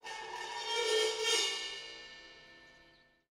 铃铛镲片 " 铃铛2反面
描述：7"黑色金属钟形钹用RODE NT1A录制到Maudio FastTrack Ultra 8R上，在Reason 6.5 DAW中反转。
Tag: 贝尔7 反向